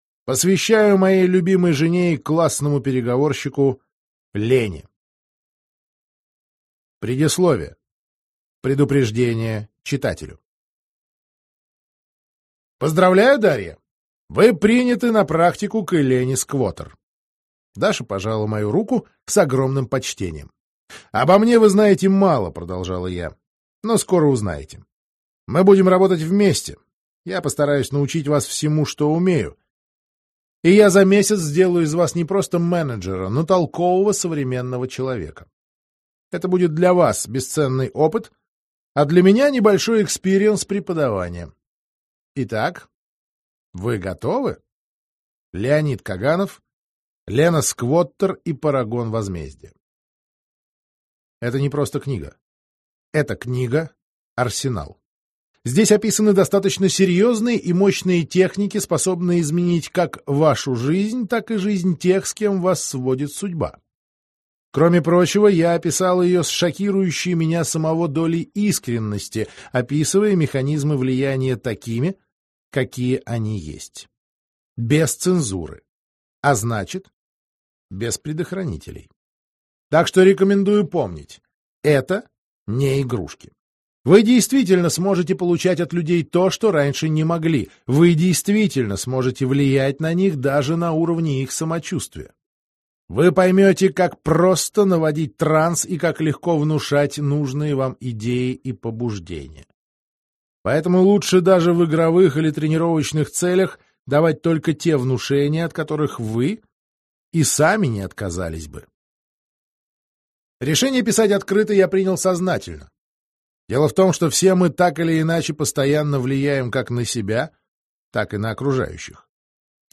Аудиокнига НЛП-технологии: Разговорный гипноз | Библиотека аудиокниг